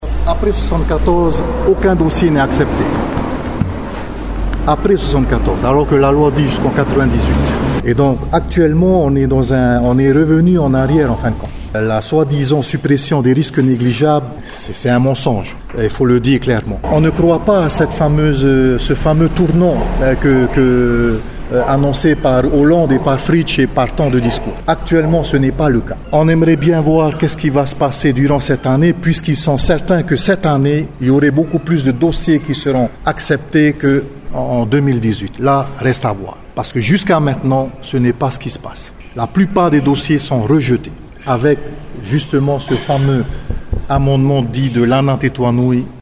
Alors que plusieurs dossiers d’indemnisations sont sur le point d’être rejetés par le Tribunal administratif, l’Association 193 qui tenait ce matin une conférence de presse a qualifié la loi Morin nouvelle manière de « farce ».